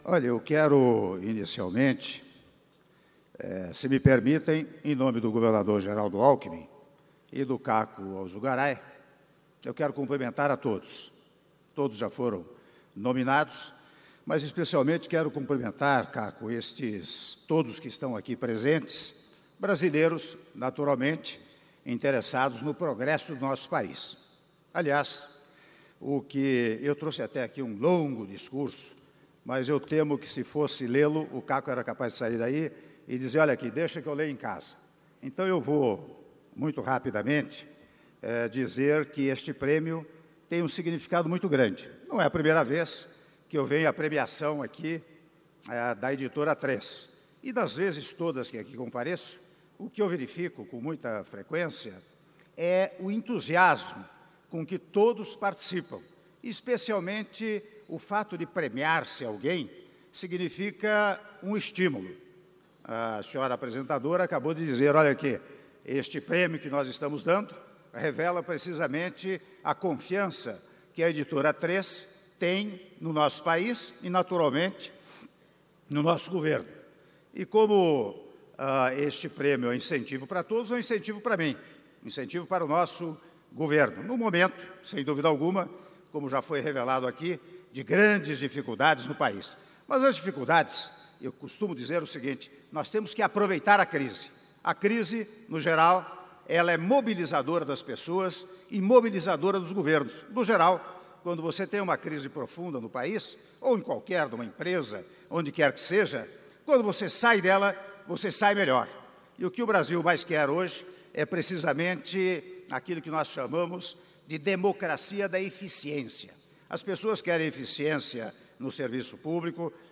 Áudio do discurso do presidente da República, Michel Temer, durante cerimônia de recebimento do Prêmio O Brasileiro do Ano 2016 - São Paulo/SP (04min15s)